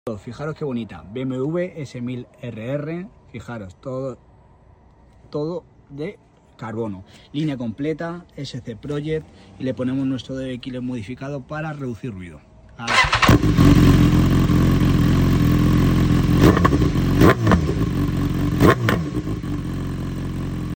🛠 The solution: we installed our premium modified db killer, designed to tone down the volume while increasing the pops 💥.
🔊 A more balanced roar 💥 Goosebump-worthy pops 😎 And an S1000RR that’s pure art… with attitude 🔥.